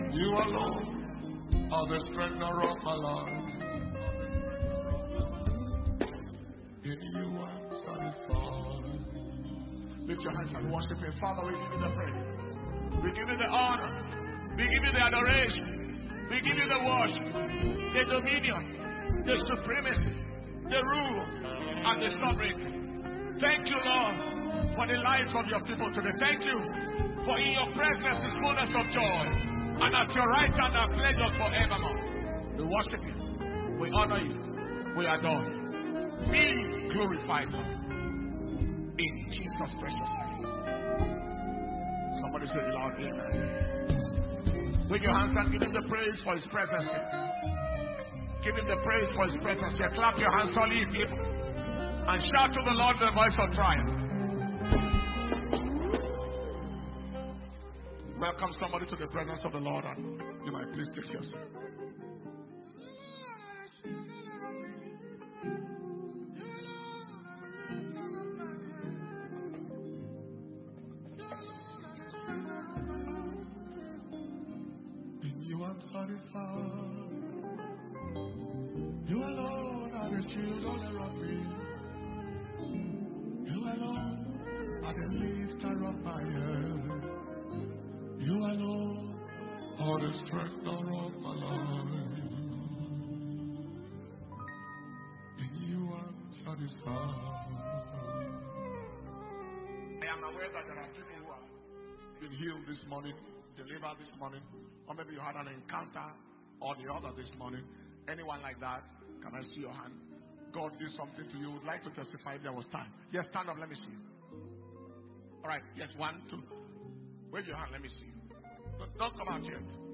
March 2023 Anointing Service